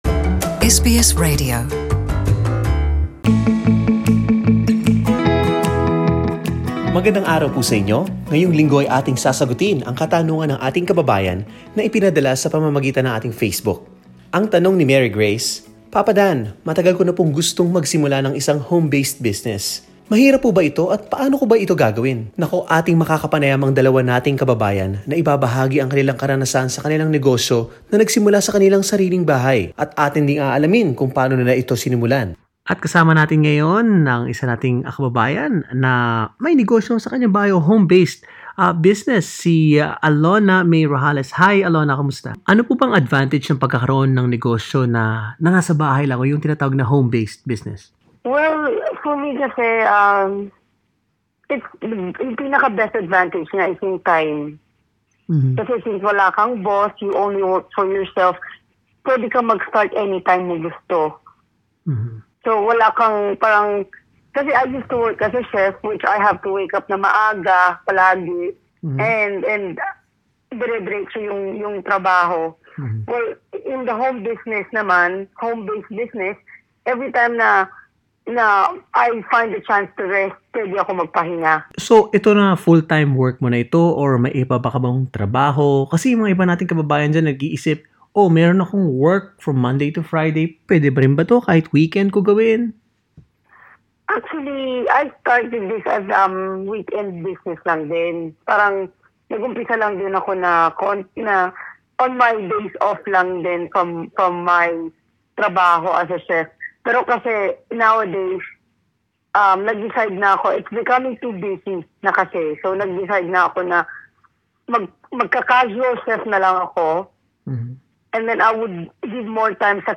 Get some tips from two former full-time workers who are now both home-based businesses owners in Sydney as they share their experiences and businesses' humble beginnings.